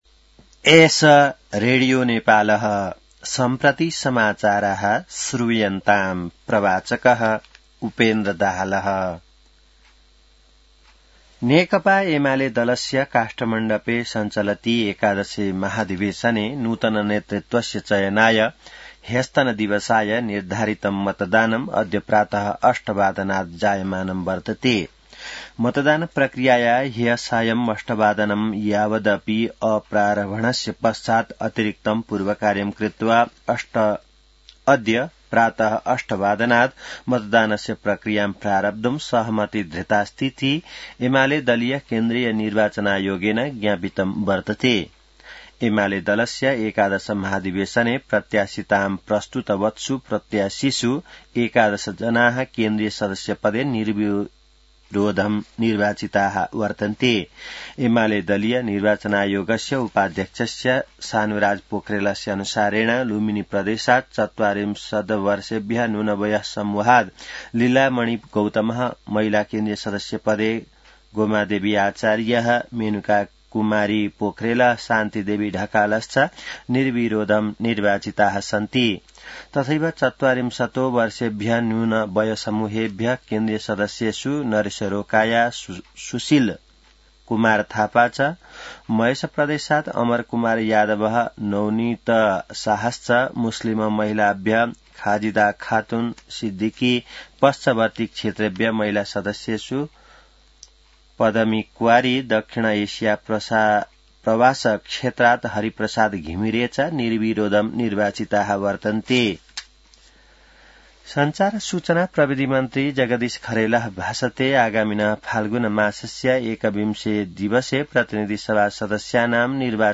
संस्कृत समाचार : २ पुष , २०८२